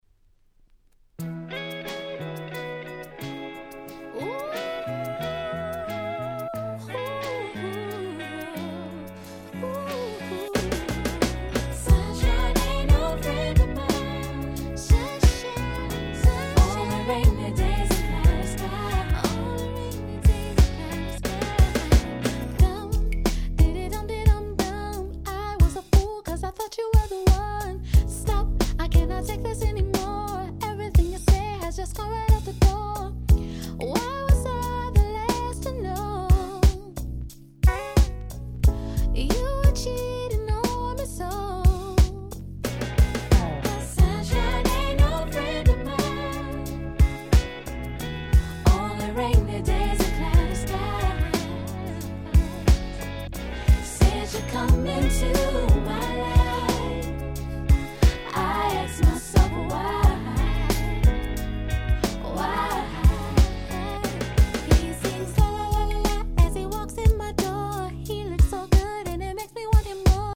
98' Nice R&B LP !!
統一したNeo Soul感が素晴らしい名盤中の名盤です！